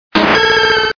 Fichier:Cri 0154 DP.ogg